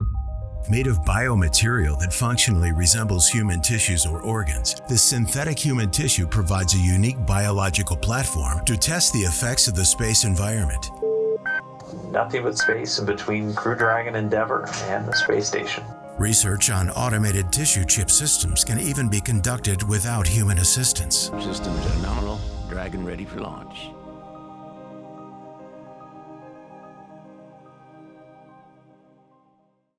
mid-Atlantic, North American English
Middle Aged
His voice is described as warm, textured, engaging, and dynamic, conveying a wide range of emotions and tones that resonate with audiences.